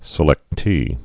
(sĭ-lĕktē)